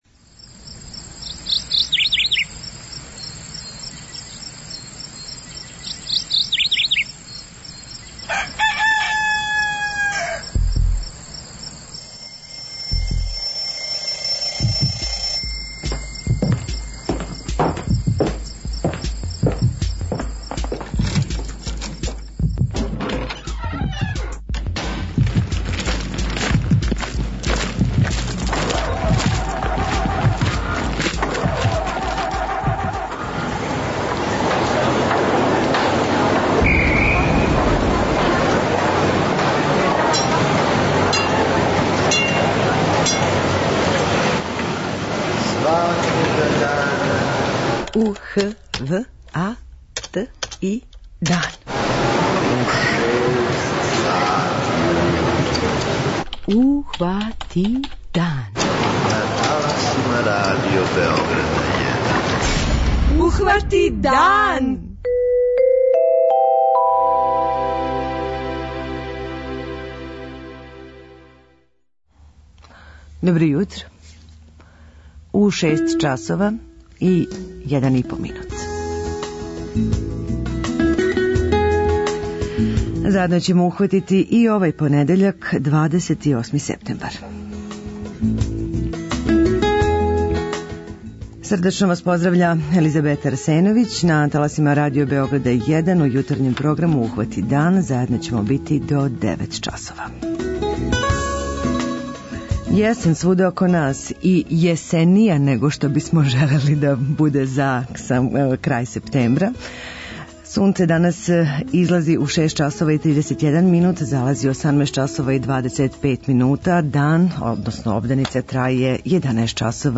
У оквиру емисије емитујемо: 06:03 Јутарњи дневник; 06:35 Догодило се на данашњи дан; 07:00 Вести; 07:05 Добро јутро децо; 08:00 Вести; 08:10 Српски на српском
- у Београду је јуче положен камен-темељац за прве објекте у оквиру пројекта "Београд на води", што је присутна јавност, на новој Савској променади, одобравала и оспоравала о чему ћете чути у звучној хроници нашег репортера;